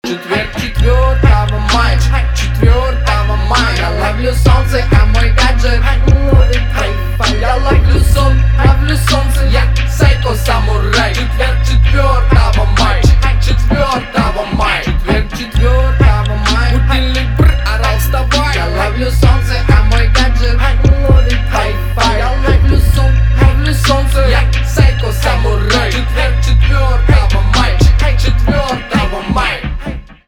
русский рэп
битовые , басы